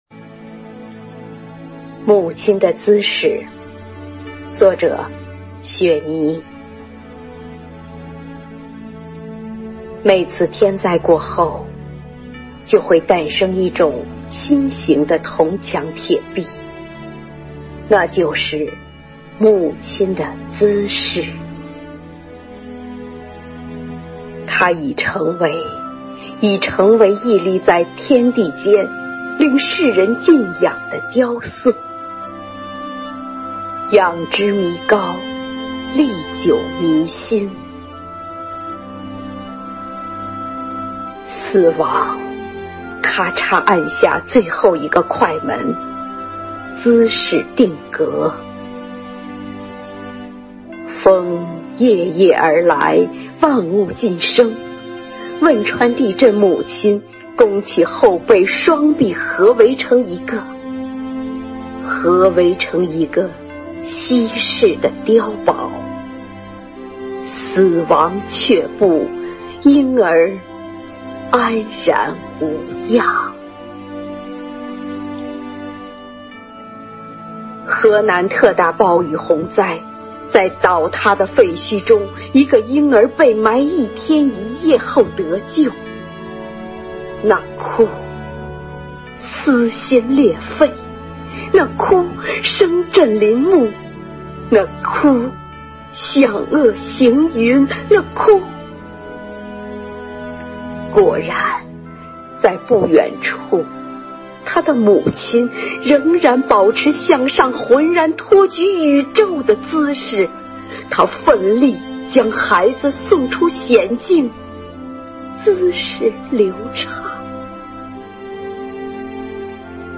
生活好课堂幸福志愿者中国钢研朗读服务（支）队第十一次云朗诵会在五月开启，声声朗诵、篇篇诗稿赞颂红五月，讴歌美好生活，吟诵美丽中国。
《母亲的姿势》朗诵